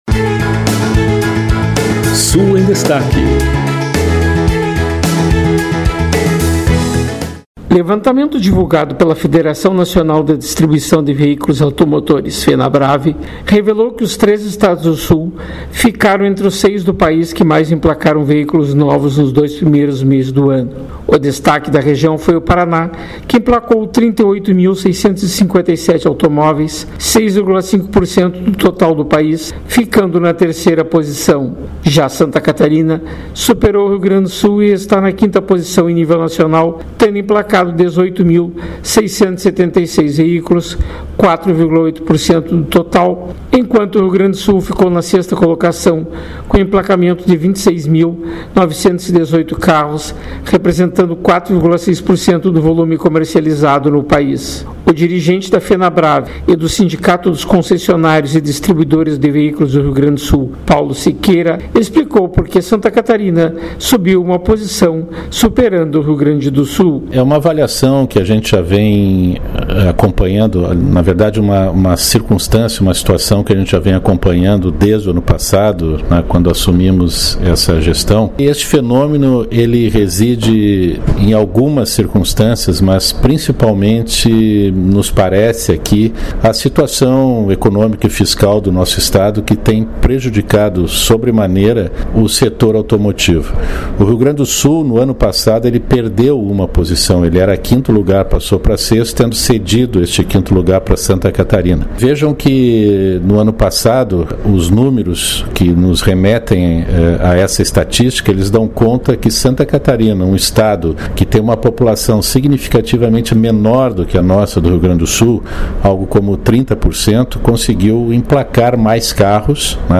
De Porto Alegre